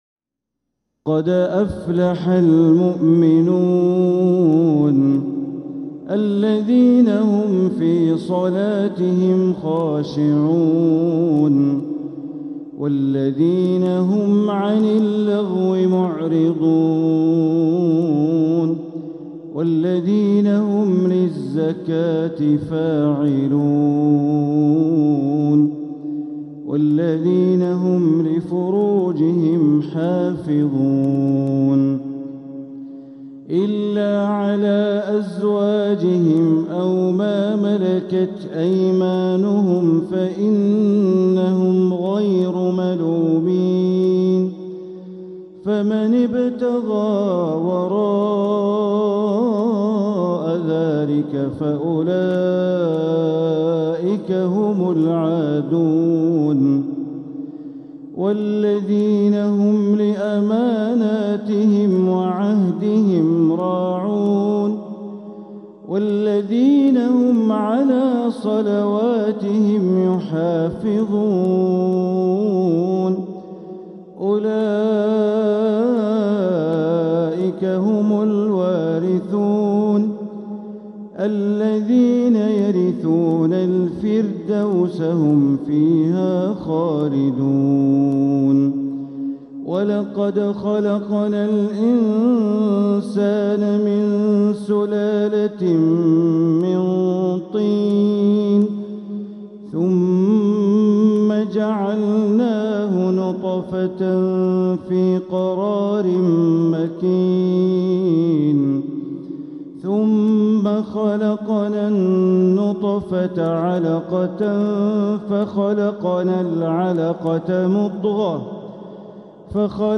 سورة المؤمنون | Surat Al-Mu'minun > السور المكتملة للشيخ بندر بليلة من الحرم المكي 🕋 > السور المكتملة 🕋 > المزيد - تلاوات الحرمين